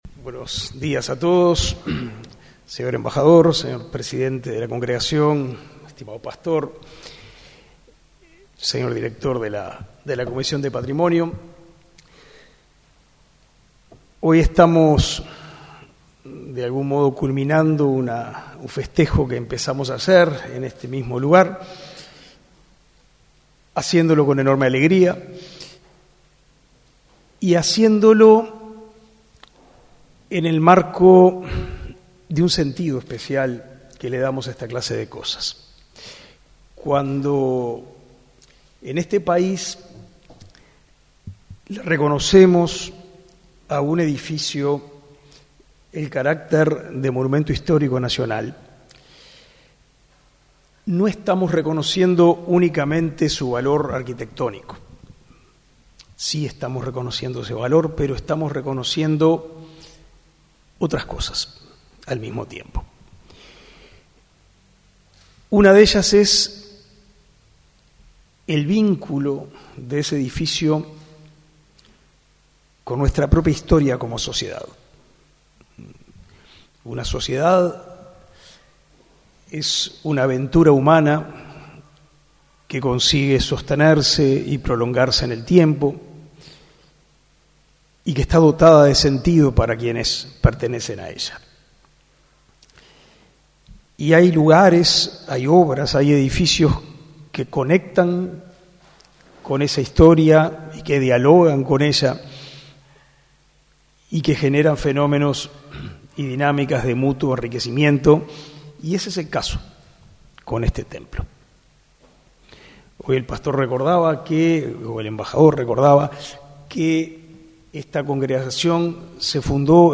Conferencia de prensa por la declaración de Monumento Histórico Nacional al Templo y Casa Pastoral de la Congregación Evangélica Alemana